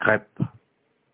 • PrononciationParis:
• IPA: [kʁɛp]